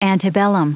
Prononciation du mot antebellum en anglais (fichier audio)
Prononciation du mot : antebellum